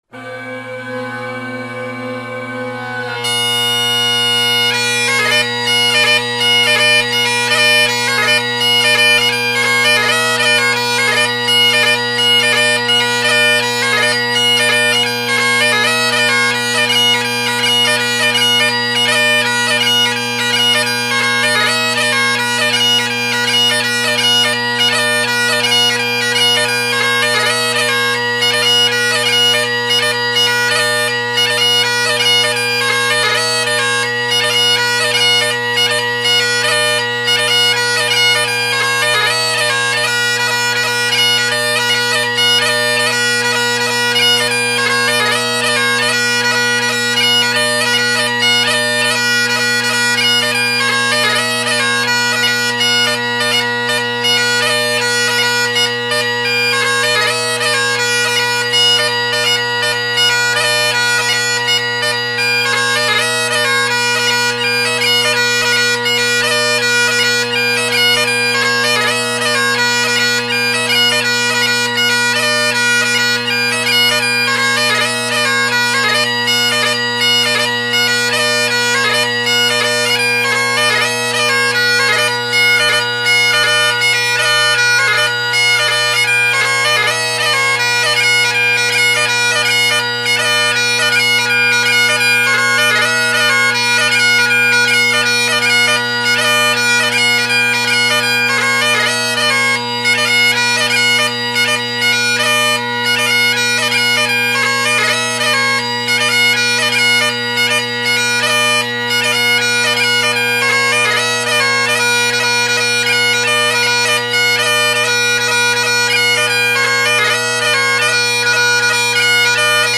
Jacky Latin (mic in front, rightish side) – same audio as the video above (recorded 2019-08-14)
Below are several recordings made over several days of most of my collection of bagpipes.